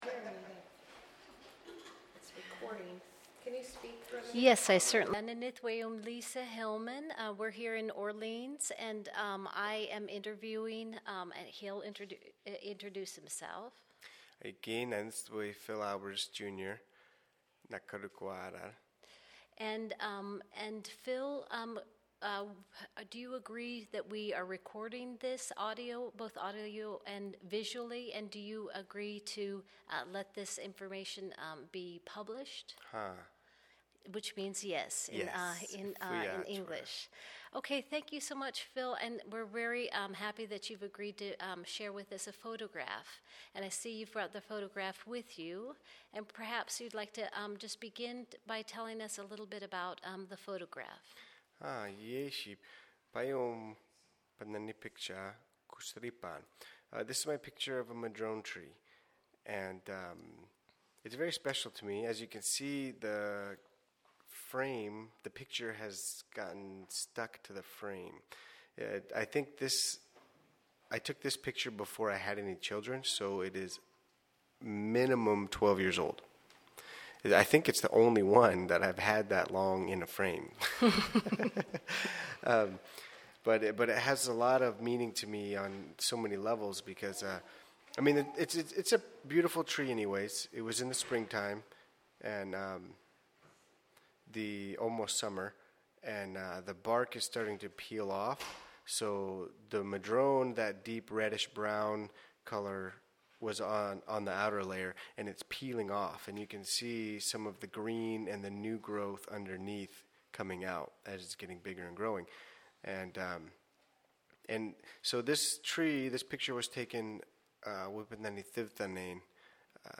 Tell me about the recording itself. Interview regarding Madrone Photo with relation to Place and People, recorded at the CoDA Digitization Training Workshop Spring 2016.